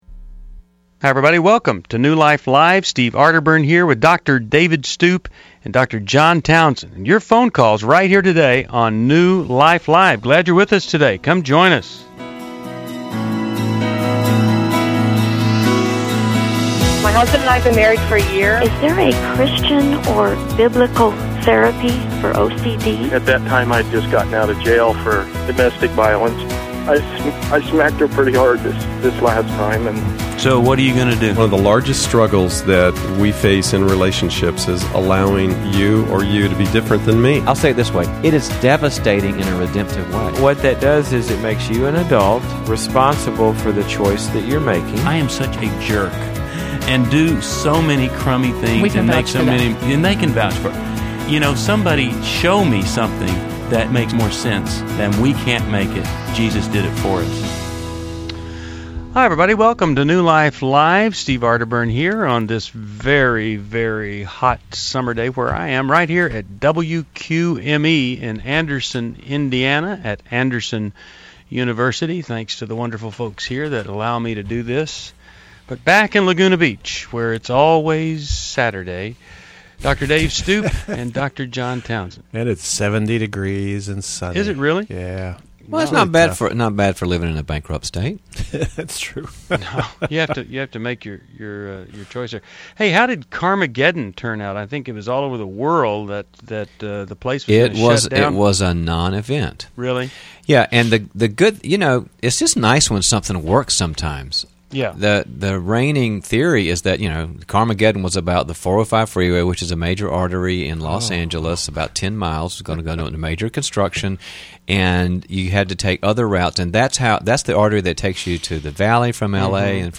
Caller Questions: 1. Is it biblical to report a pastor who was inappropriate towards me? 2.